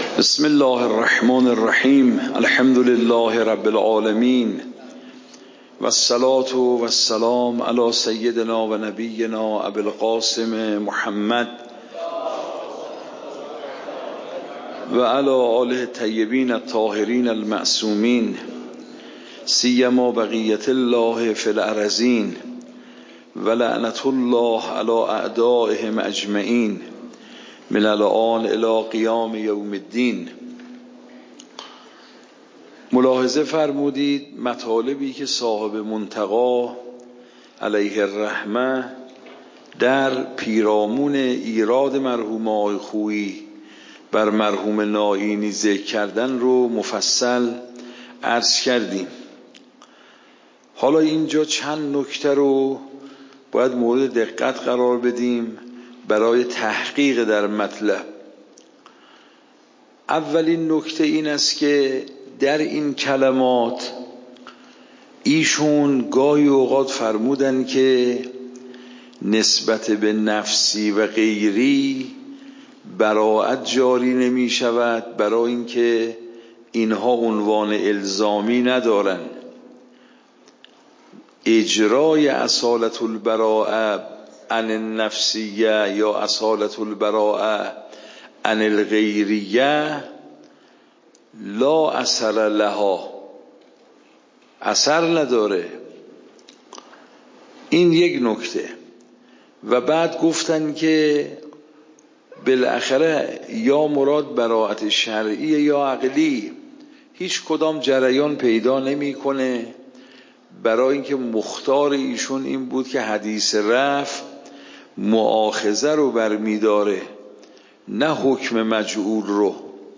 درس بعد واجب نفسی و غیری درس قبل واجب نفسی و غیری درس بعد درس قبل موضوع: واجب نفسی و غیری اصول فقه خارج اصول (دوره دوم) اوامر واجب نفسی و غیری تاریخ جلسه : ۱۴۰۴/۷/۷ شماره جلسه : ۱۱ PDF درس صوت درس ۰ ۱۸۴